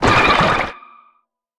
Sfx_creature_penguin_flinch_sea_01.ogg